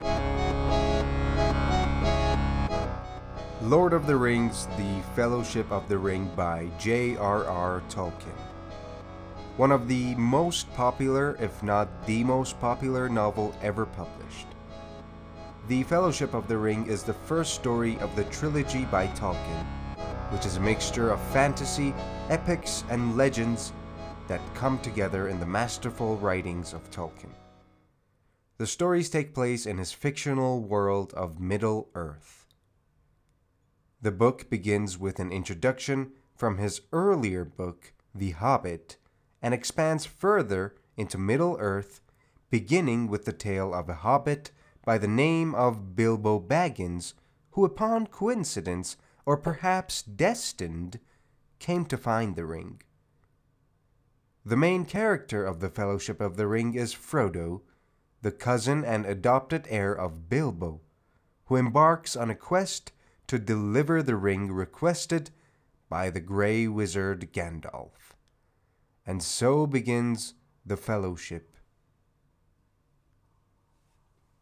معرفی صوتی کتاب The Lord of the Rings: The Fellowship of the Ring